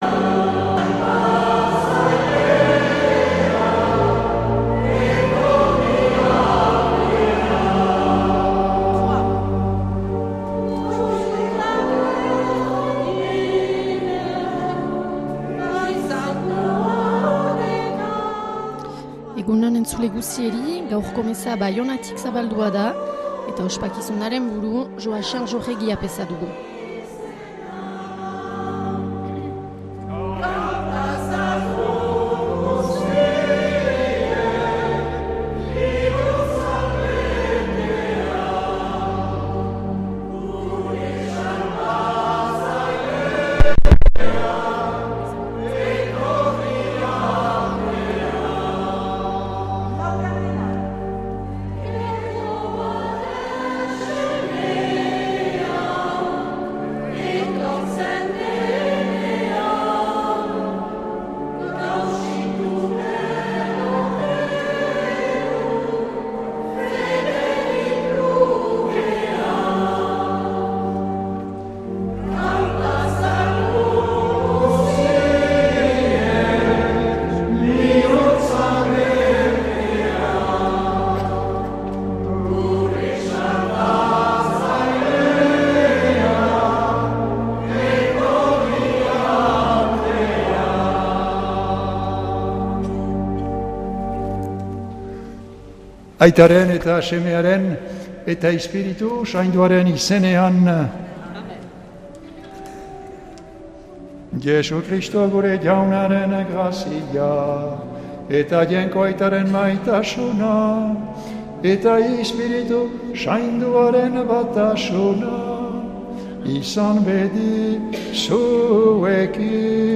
Accueil \ Emissions \ Vie de l’Eglise \ Célébrer \ Igandetako Mezak Euskal irratietan \ 2025-12-21 Abenduko 4.